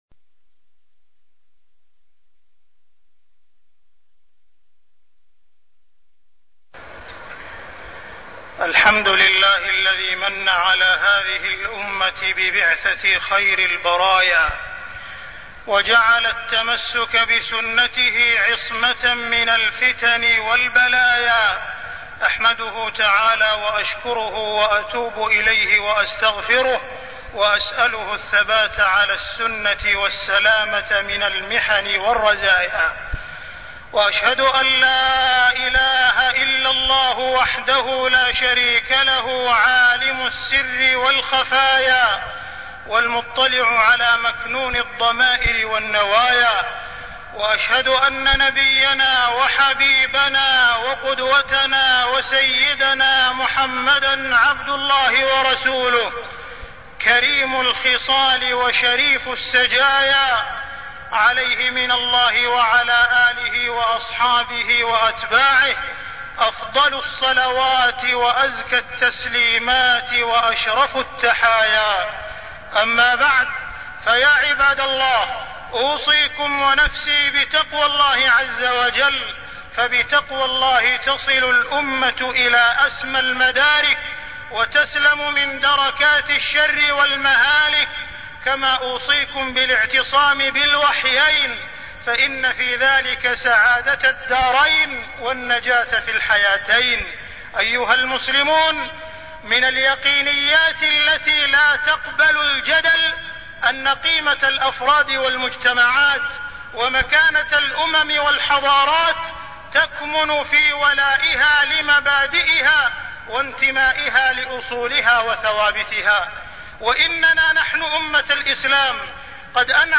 تاريخ النشر ١٨ ربيع الأول ١٤٢٠ هـ المكان: المسجد الحرام الشيخ: معالي الشيخ أ.د. عبدالرحمن بن عبدالعزيز السديس معالي الشيخ أ.د. عبدالرحمن بن عبدالعزيز السديس اتباع الكتاب والسنة